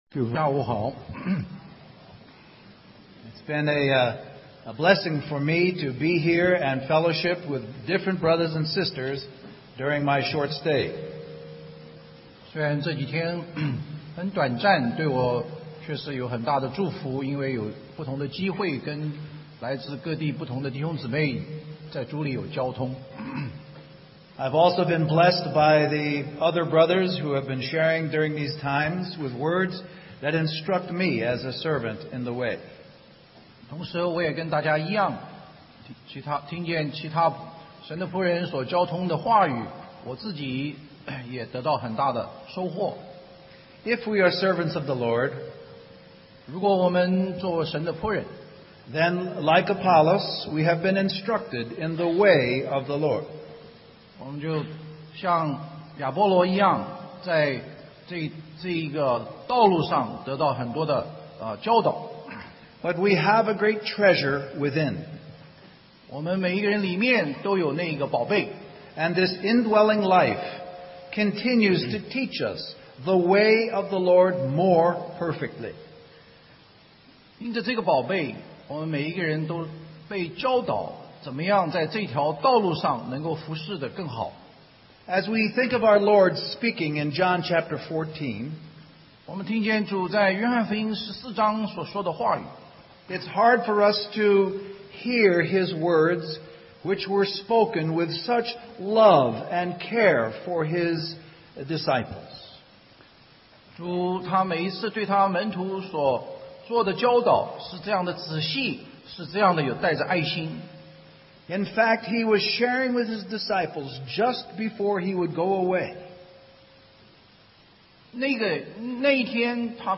2009 Special Conference For Service, Singapore Stream or download mp3 Summary Our brother shares from the conference theme of "Christ Centered Service".